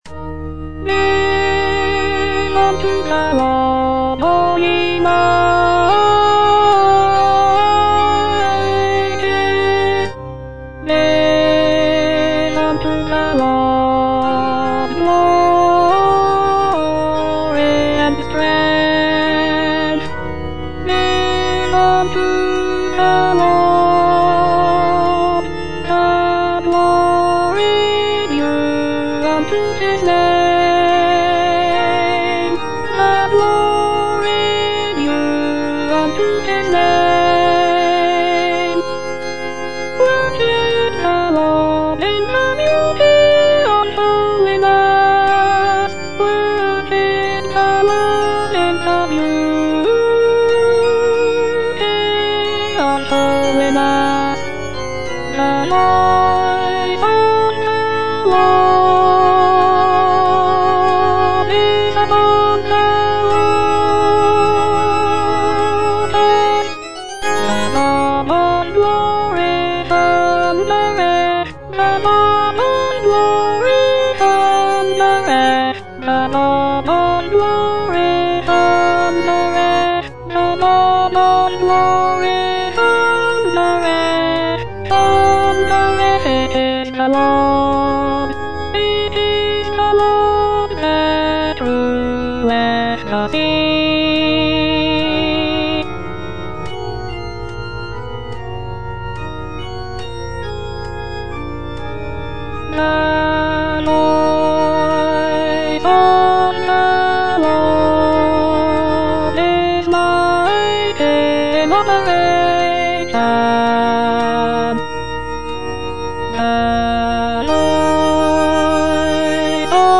E. ELGAR - GIVE UNTO THE LORD Alto II (Voice with metronome) Ads stop: auto-stop